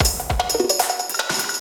CRAZ BL LOOP 1.wav